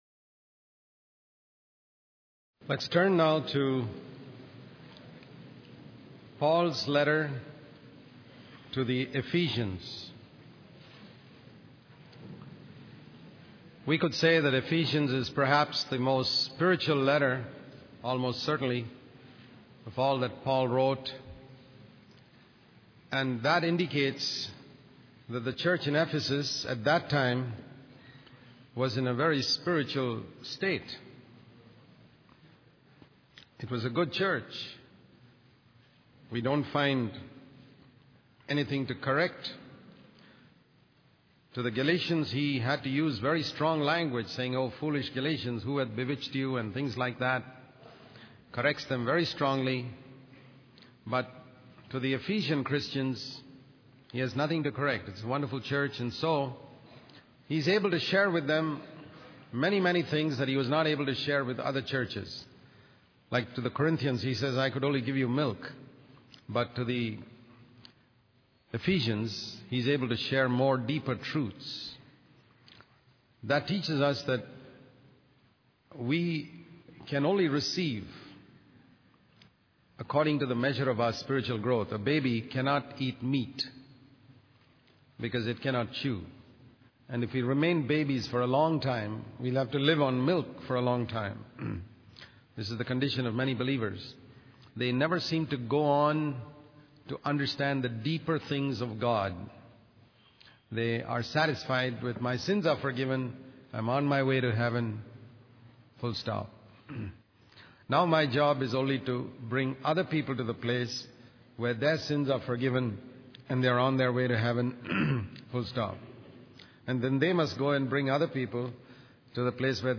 In this sermon, the speaker emphasizes the importance of understanding the foundation of the Christian faith before moving on to the practical aspects of living a Christlike life. The first three chapters of Ephesians are highlighted as the foundation, where believers are reminded of what God has done for them. The speaker explains that without this foundation, any attempts to imitate Christ externally will be in vain.